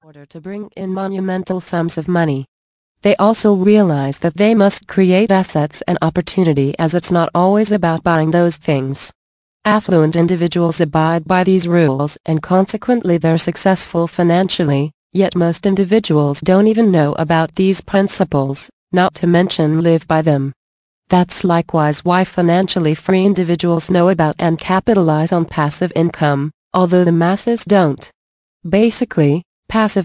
Thanks for your interest in this audio book.